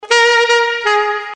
巴拉卡萨的次中音萨克斯12
标签： 80 bpm Chill Out Loops Woodwind Loops 320.00 KB wav Key : Unknown
声道立体声